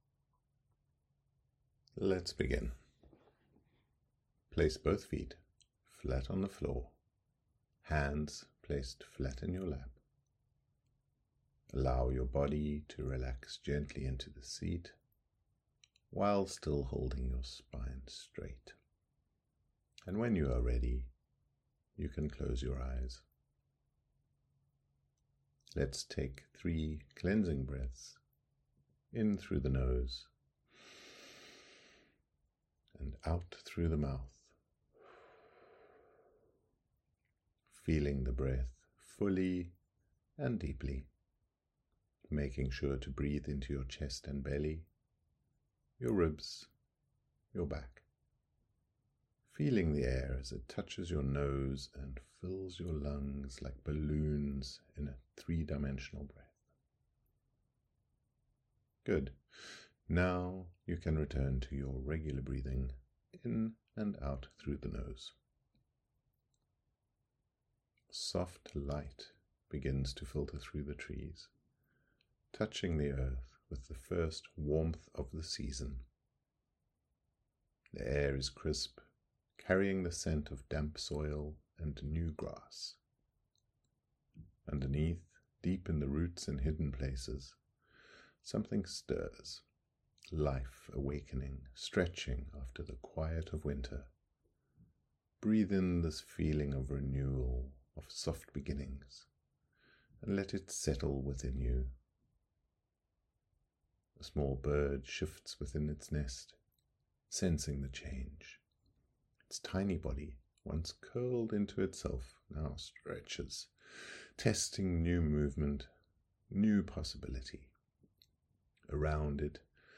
Workshop Meditation
YE04-meditation-bud-branch.mp3